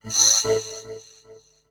Techno / Voice / VOICEFX161_TEKNO_140_X_SC2(R).wav